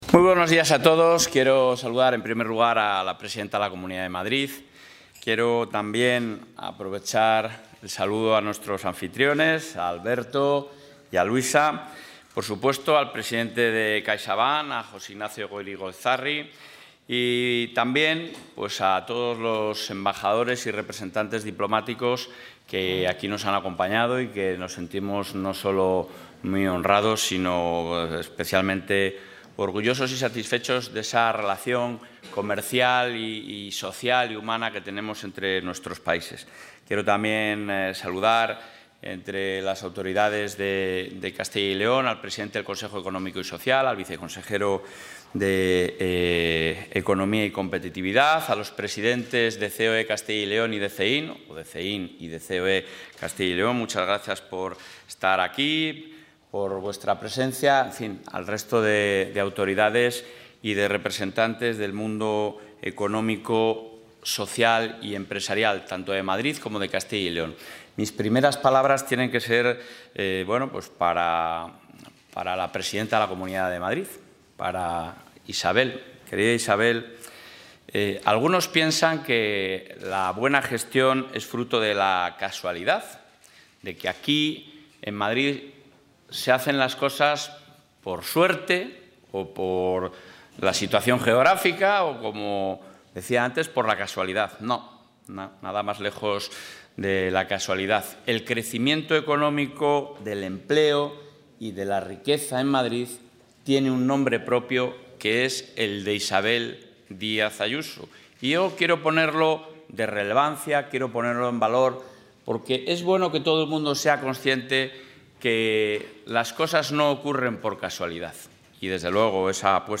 Intervención presidente.
En la clausura del VIII Foro Guadarrama, junto a la presidenta de la Comunidad de Madrid, Isabel Díaz Ayuso, el presidente de la Junta, Alfonso Fernández Mañueco, ha asegurado una revolución fiscal en Castilla y León que va a dejar más de 2.500 millones en el bolsillo de los ciudadanos.